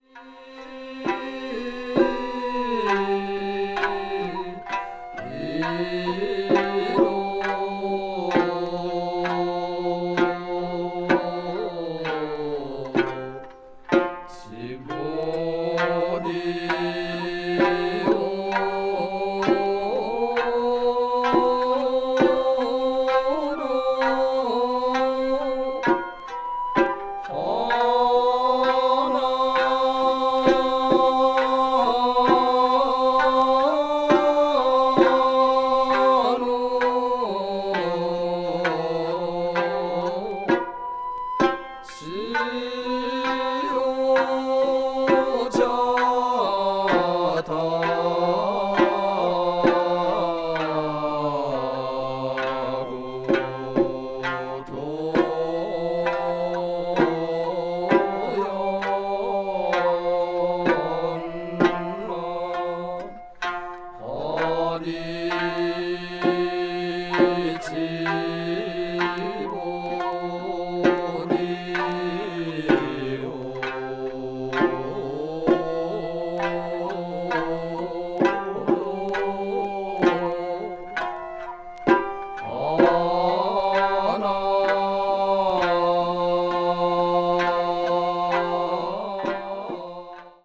media : EX-/EX,EX/EX(わずかにチリノイズが入る箇所あり,A5:軽いプチノイズ2回あり)
録音も非常に秀逸。
ethnic music   japan   minyo   okinawa   ryukyu   traditional